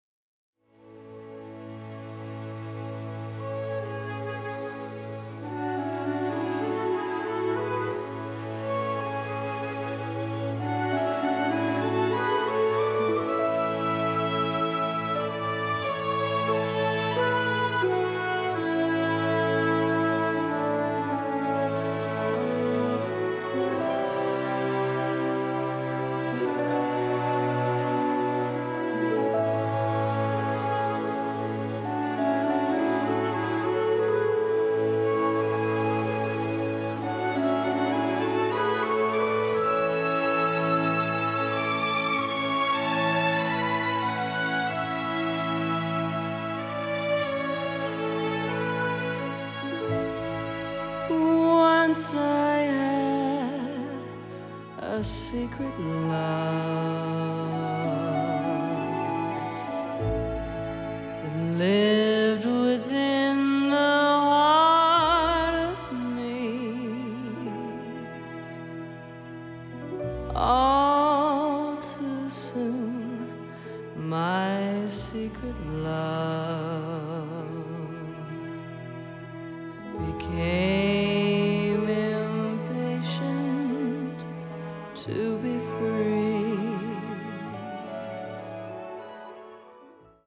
a touching arrangement of the song